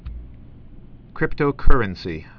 (krĭptō-kûrən-sē, -kŭr-)